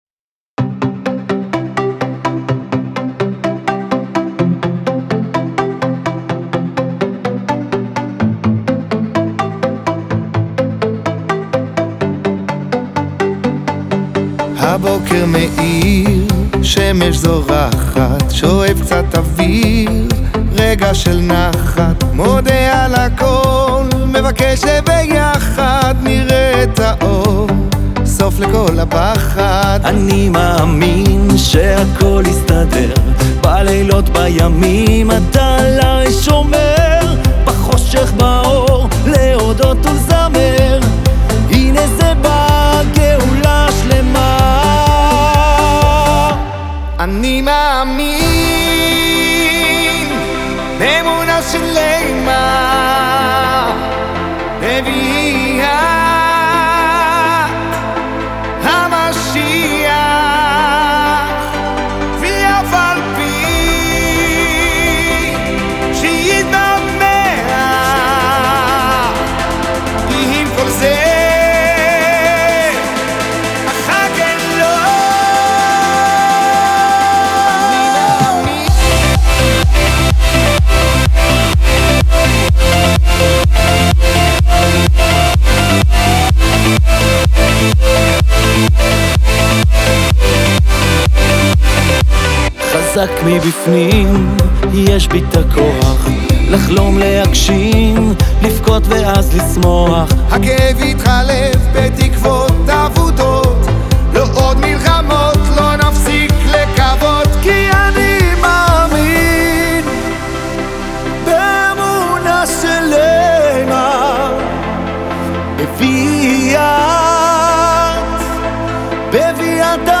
דואט חדש דנדש